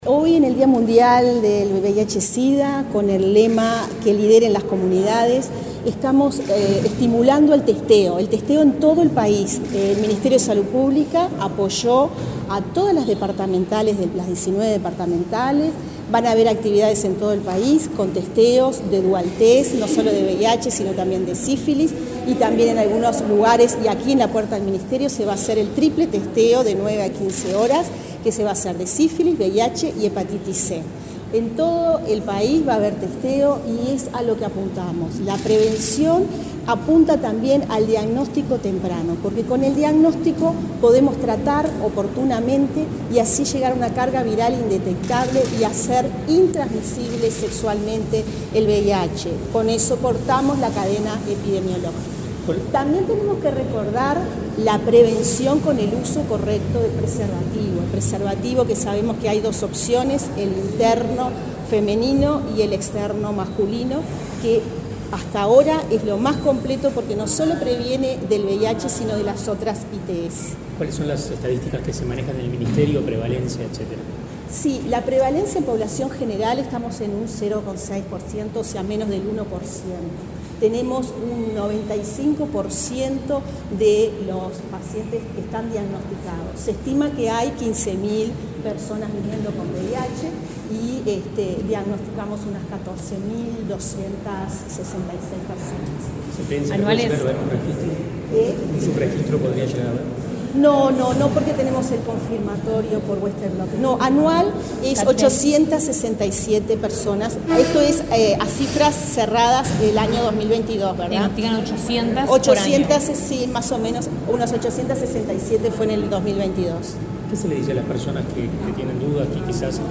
dialogó con la prensa en el marco del acto por el Día Mundial del Sida.